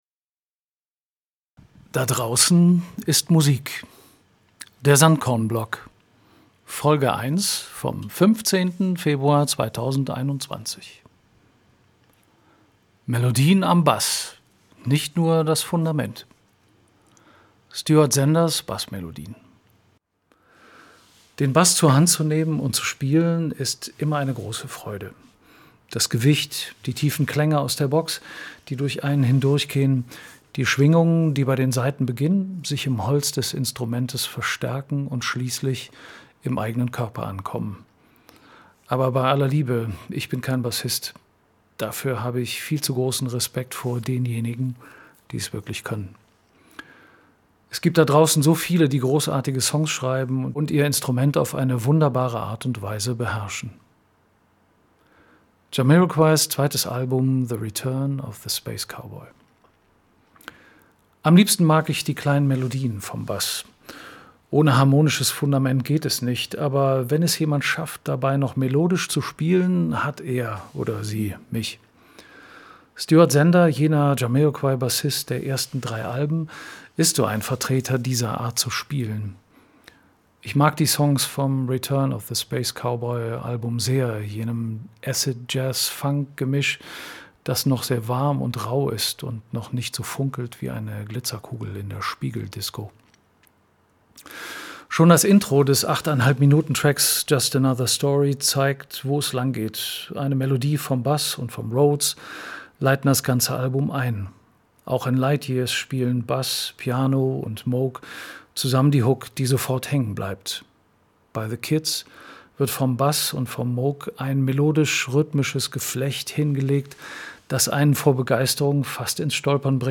Melodien am Bass, nicht nur das Fundament!